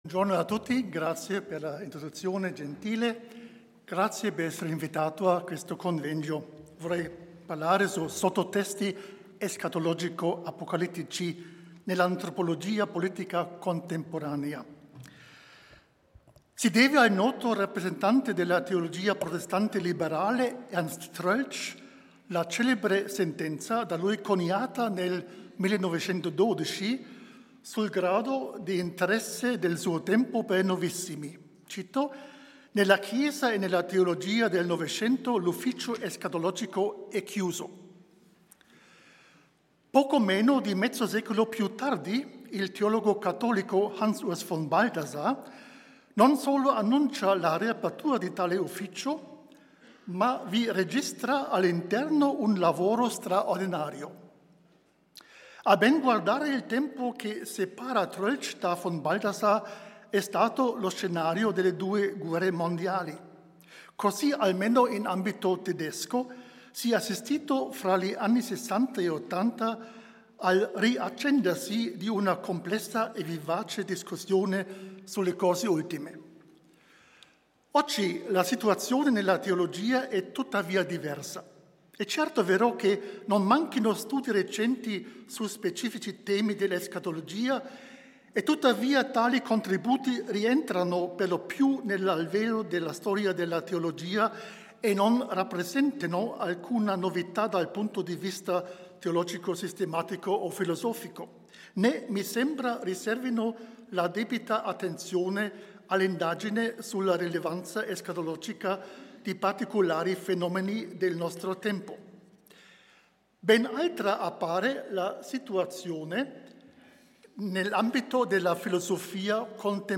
Convegno di Studio 2019 Audio – Facoltà Teologica dell'Italia Settentrionale